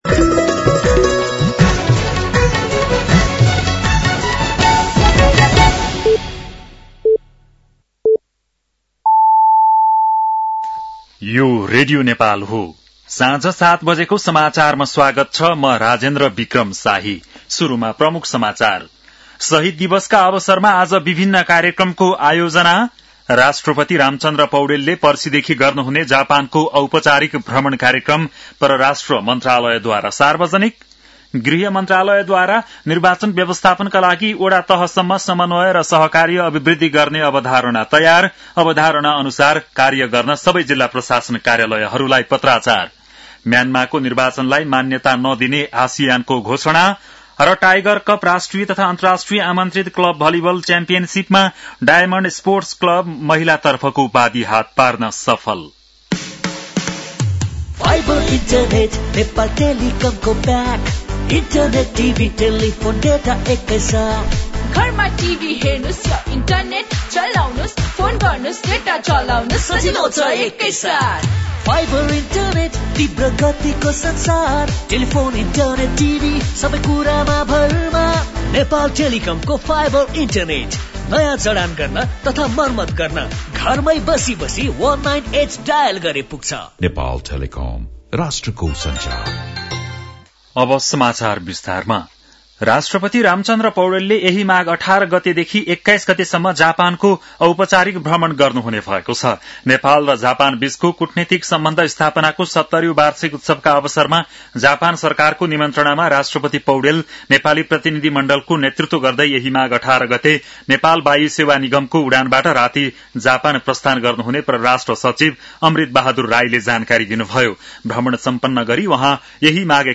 बेलुकी ७ बजेको नेपाली समाचार : १६ माघ , २०८२
7-pm-nepali-news-10-16.mp3